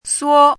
怎么读
suō